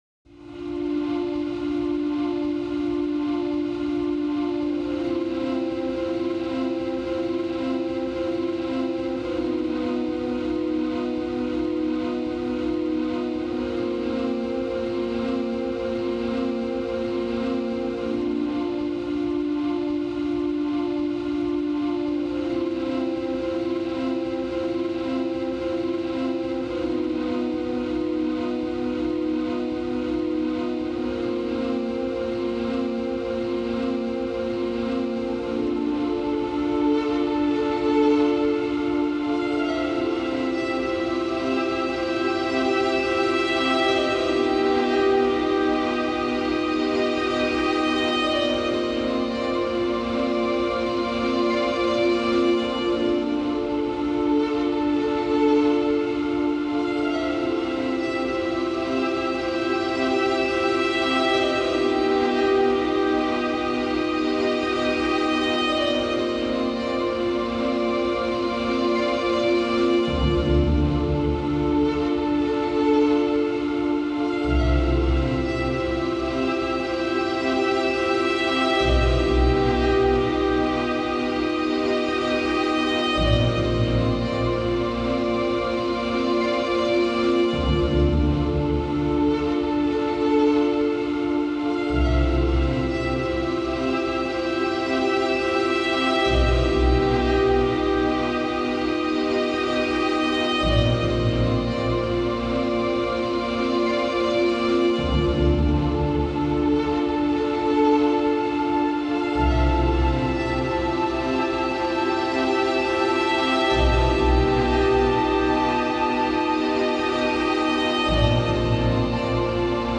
Pain and doubt stretched across aching strings.